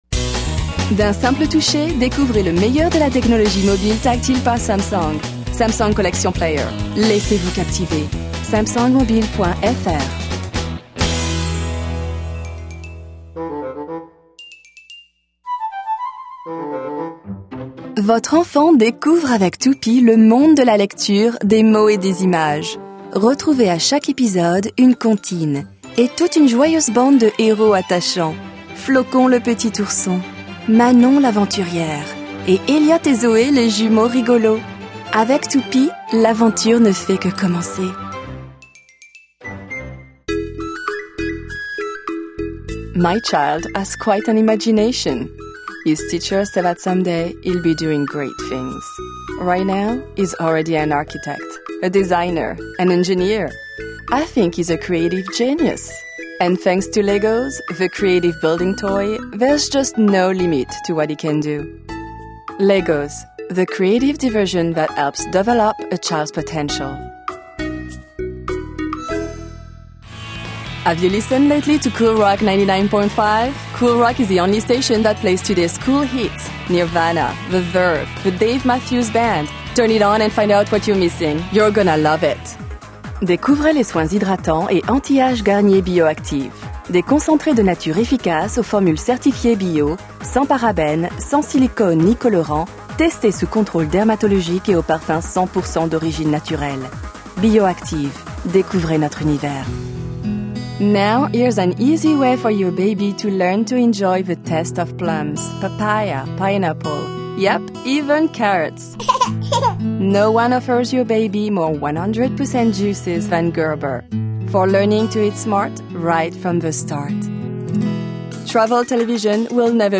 Native French Voice Over artist.
Professional home studio, based in New York. 15+ years experience.
Sprechprobe: Sonstiges (Muttersprache):